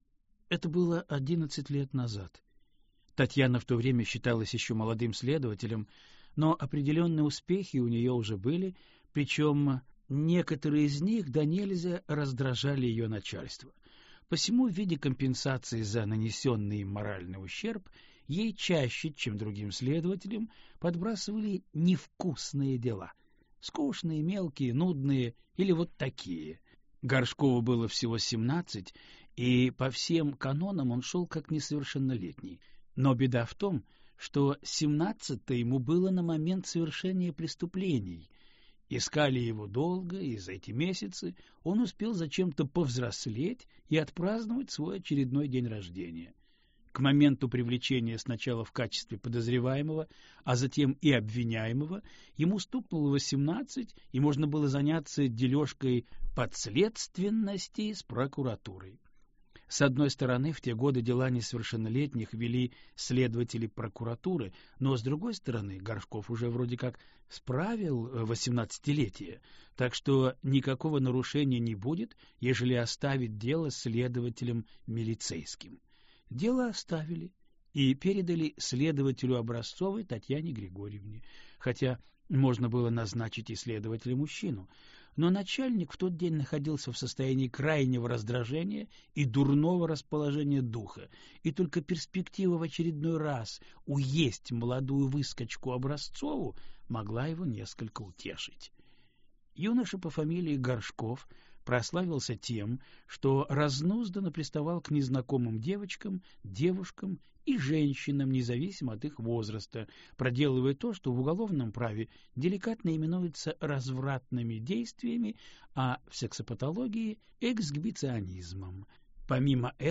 Аудиокнига Седьмая жертва - купить, скачать и слушать онлайн | КнигоПоиск